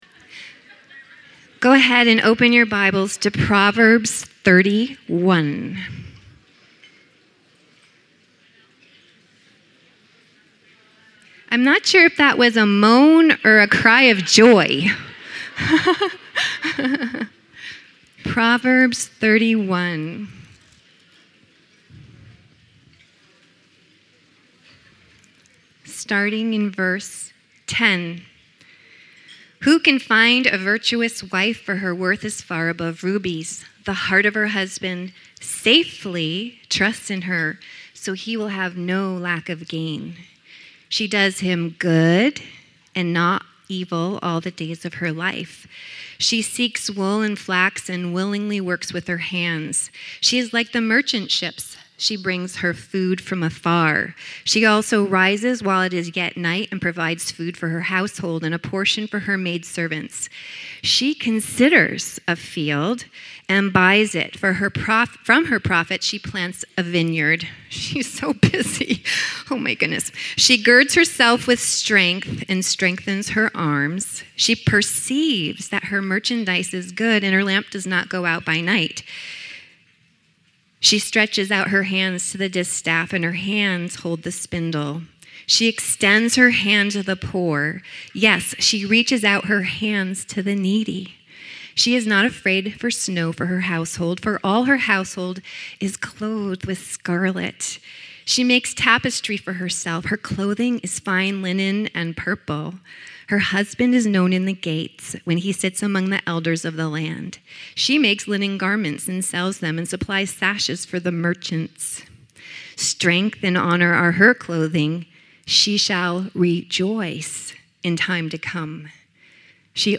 Women's Retreat 2015: Pearl's of Wisdom